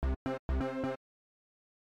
描述：有趣的旋律的东西
标签： 130 bpm Funk Loops Synth Loops 333.04 KB wav Key : Unknown
声道立体声